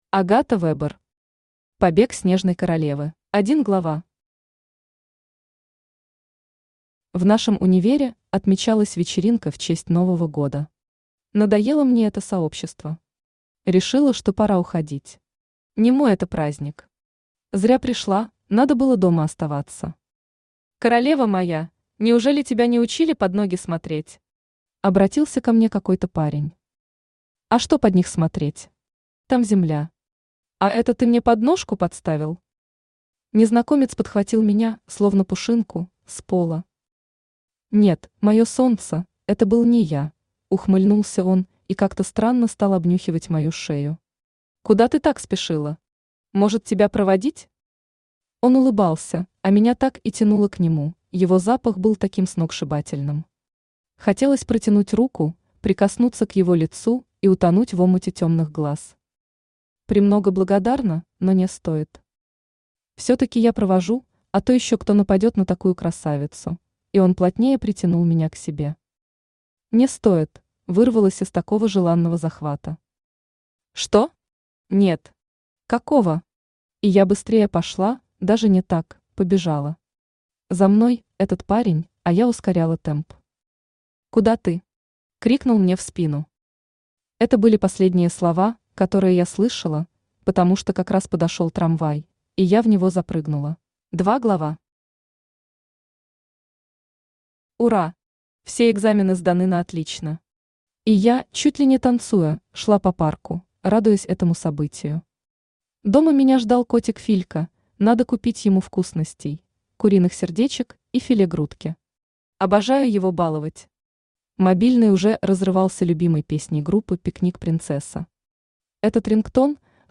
Аудиокнига Побег Снежной королевы | Библиотека аудиокниг
Aудиокнига Побег Снежной королевы Автор Агата Вебер Читает аудиокнигу Авточтец ЛитРес.